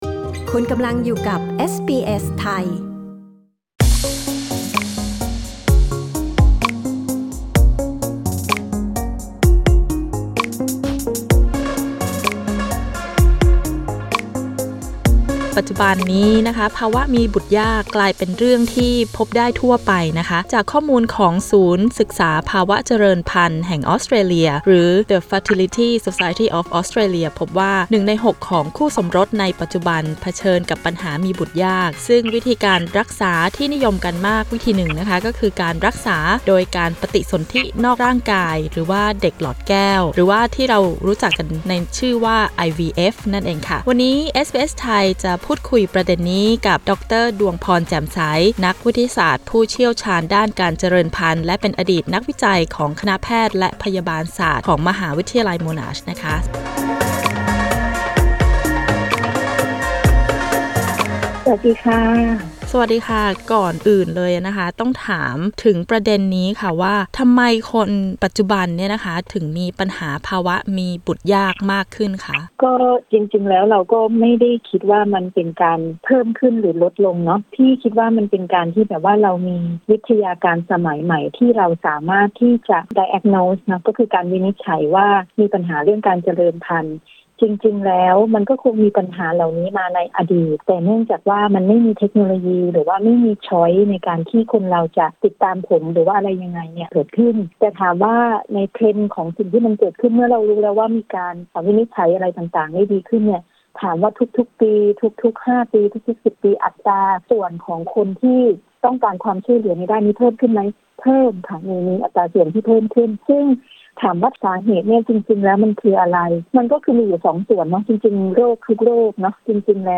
กดปุ่ม 🔊 ด้านบนเพื่อฟังสัมภาษณ์เรื่องนี้